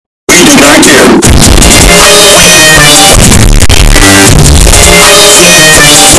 Loud Sound Extremem Button Sound Button - Free Download & Play